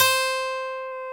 Index of /90_sSampleCDs/Roland L-CD701/GTR_Steel String/GTR_ 6 String
GTR 6-STR311.wav